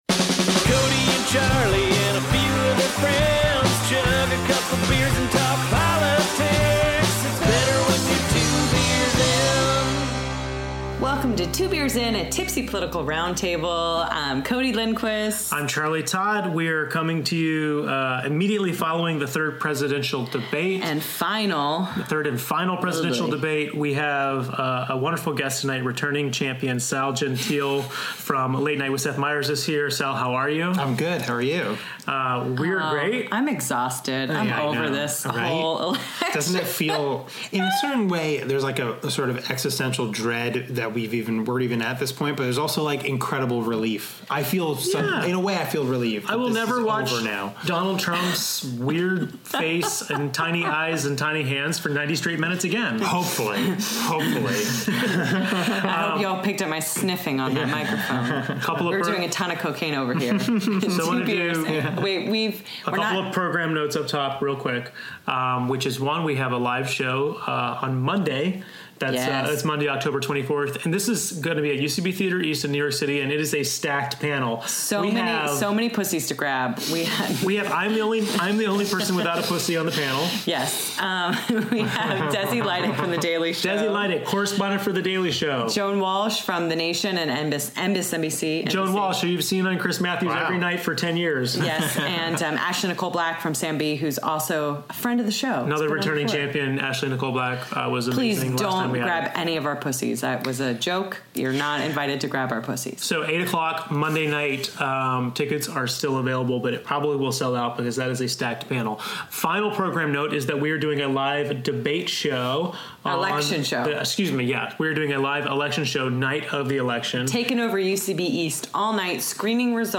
joins us for tipsy commentary after the 3rd and final debate.